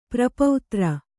♪ prapautra